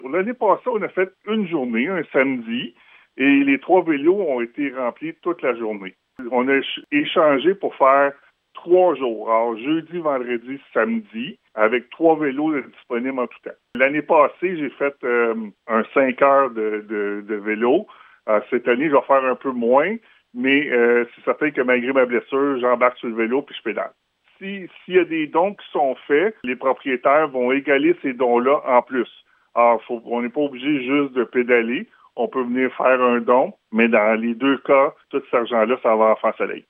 En entrevue avec le service de nouvelles de M105